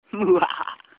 Mwuahahahaha